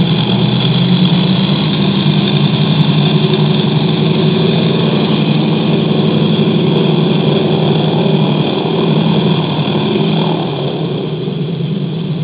DC-3 Sound Files
Taking off in the distance (outside recording).